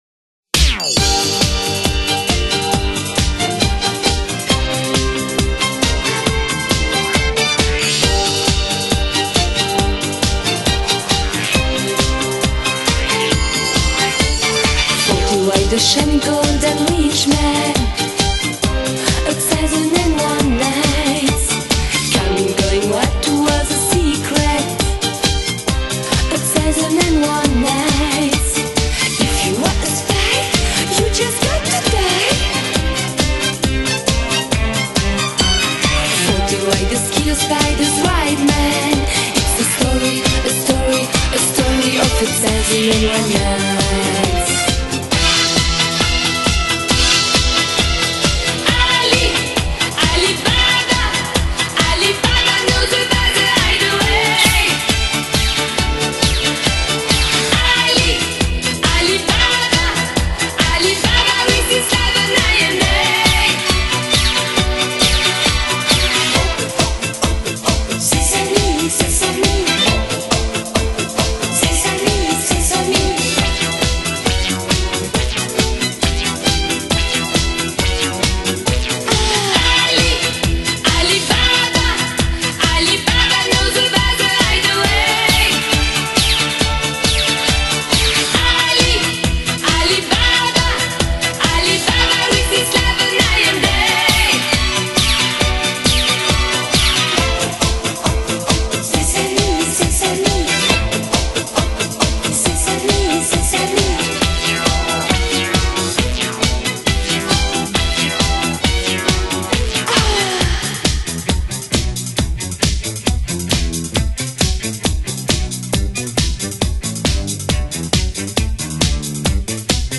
是80年代非常走红的一首舞曲